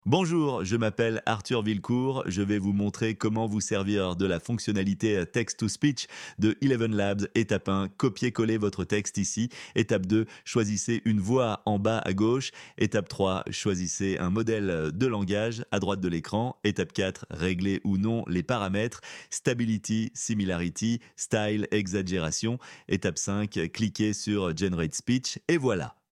Text-to-Speech : Transformez du texte en voix
Choisissez une voix-off (ici j’ai sélectionné Nicolas animateur).
Voici le rendu avec une des voix de ElevenLabs :
Dans l’exemple ci-dessous, imaginons que la voix d’homme (Nicolas animateur) ne me plaise pas.
test-fonctionnalite-text-to-speech-elevenlabs.mp3